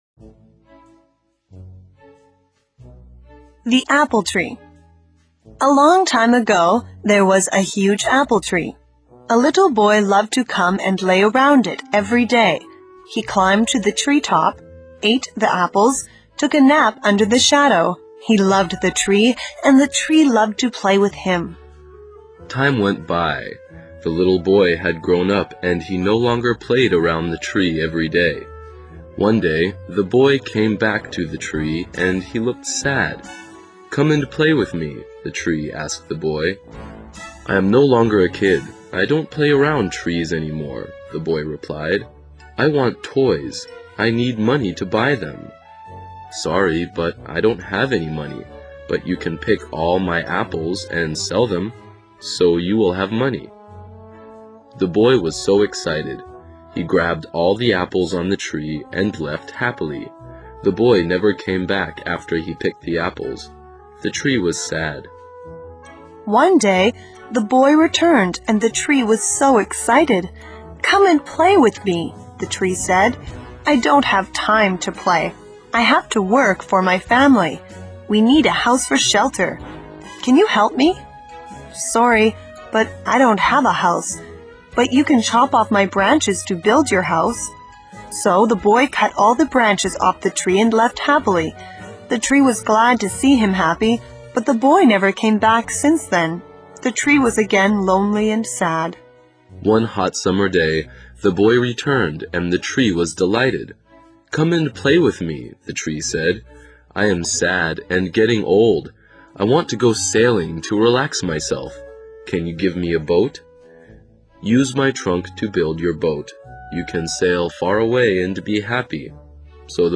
英文故事剧 苹果树(The Apple Tree) 听力文件下载—在线英语听力室